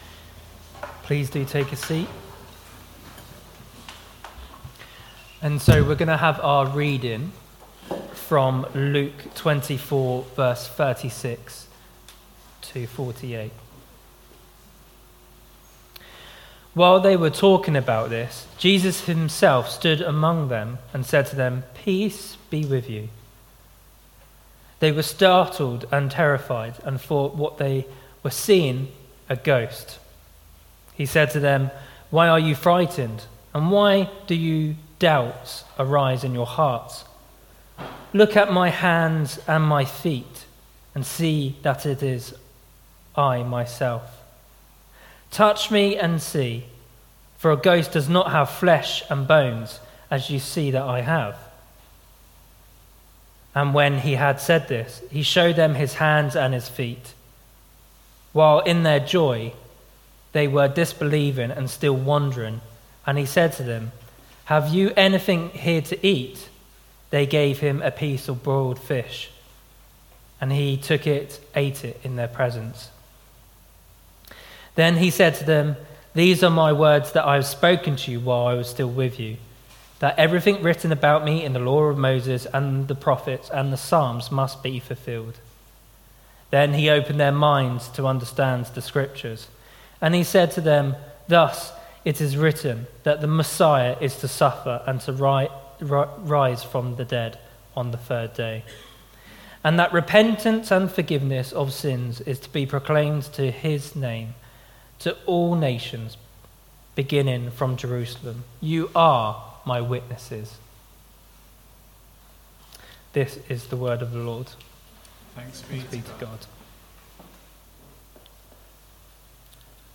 Sermon 14th April 2024 11am gathering
We have recorded our talk in case you missed it or want to listen again.